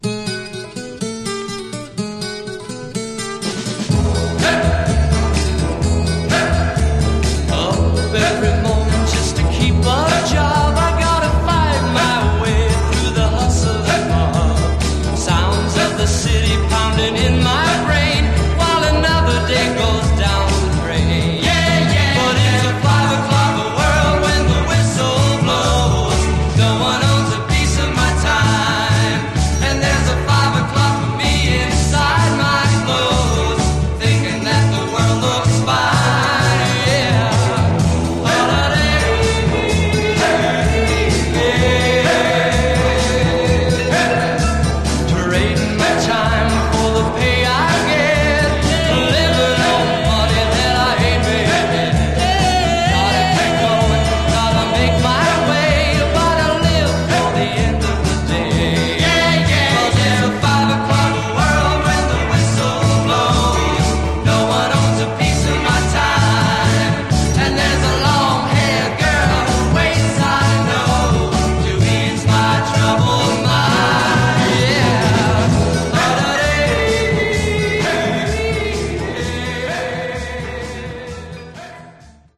Genre: Sunshine Pop